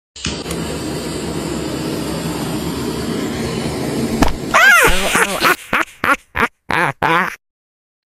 Cat Funny reaction 😂😂 . sound effects free download